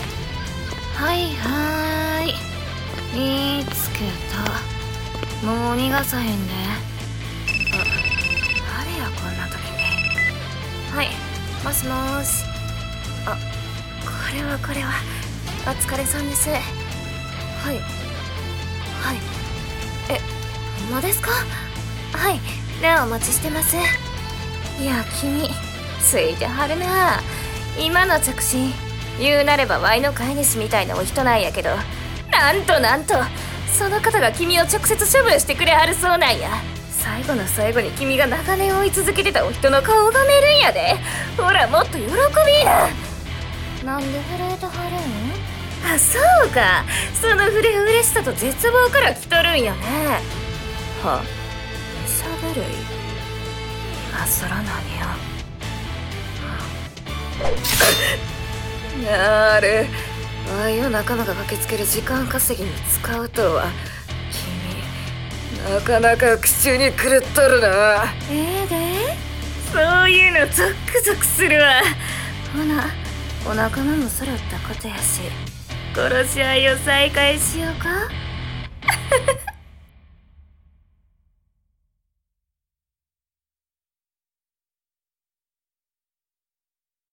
【朗読】復讐の聖戦